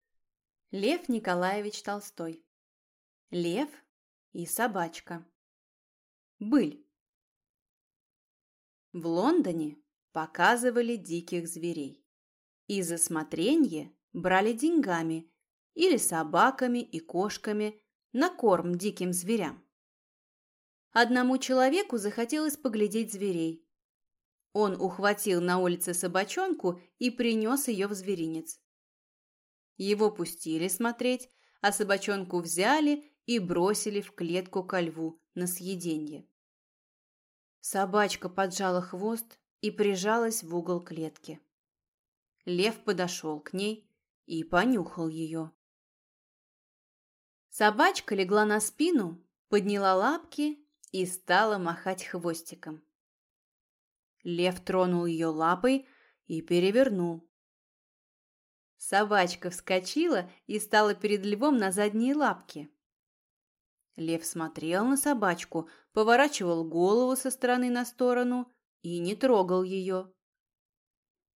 Аудиокнига Лев и собачка | Библиотека аудиокниг